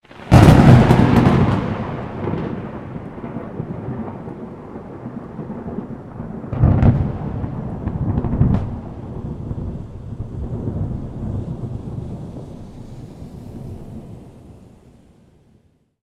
thunder_23.ogg